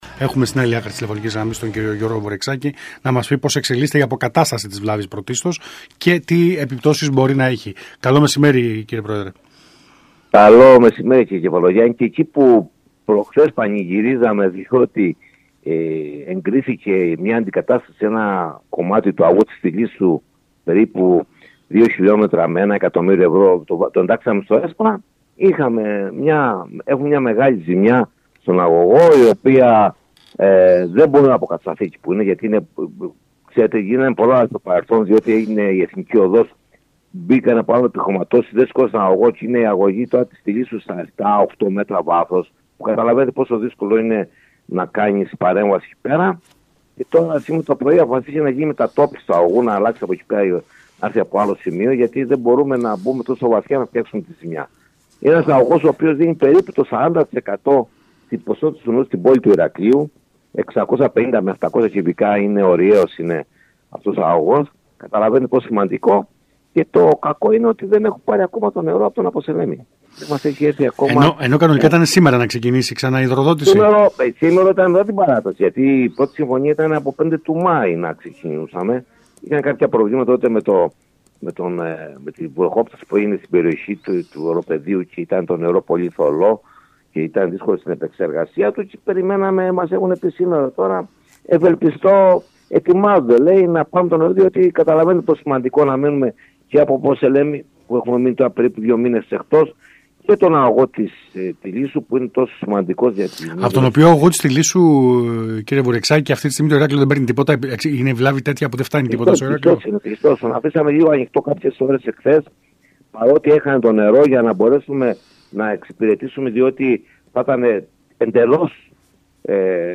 μιλώντας το μεσημέρι της Παρασκευής 16 Μάϊου στον ΣΚΑΙ Κρήτης 92.1 εξέφρασε την ανησυχία του